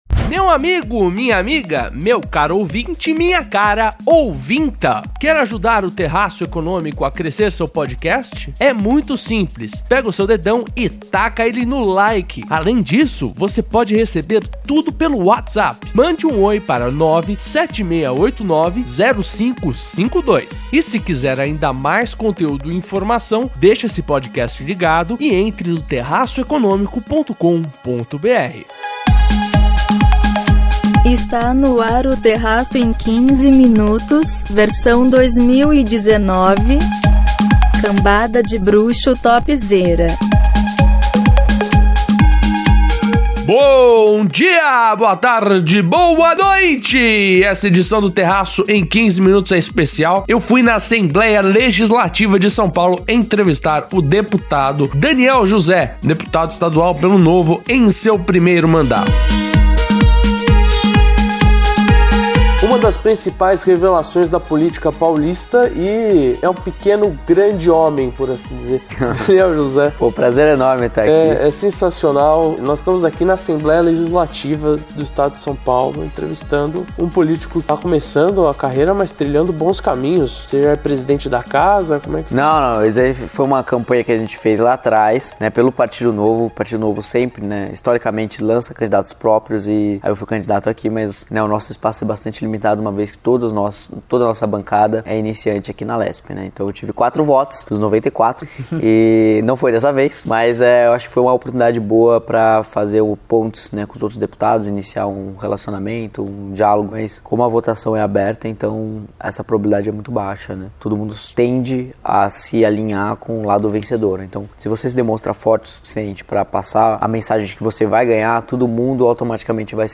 Nesta edição, confira entrevista com Daniel José, deputado estadual em São Paulo.